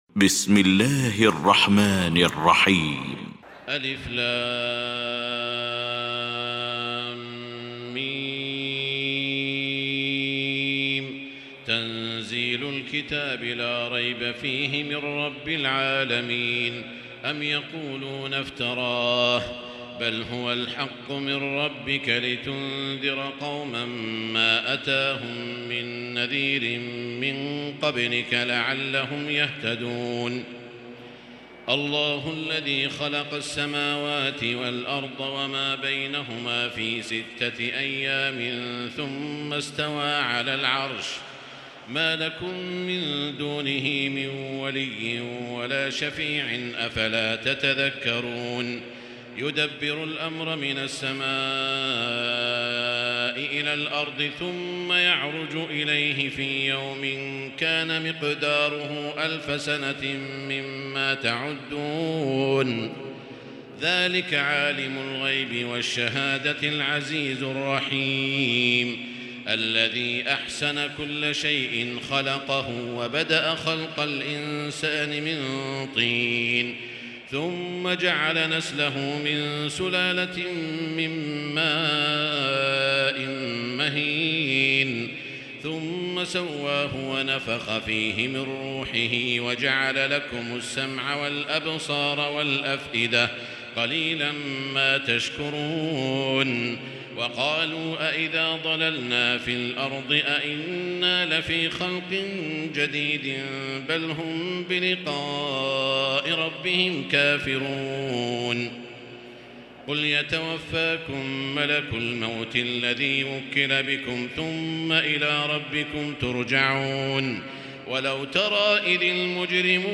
المكان: المسجد الحرام الشيخ: سعود الشريم سعود الشريم السجدة